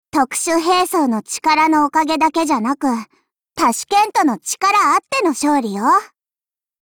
碧蓝航线:塔什干(μ兵装)语音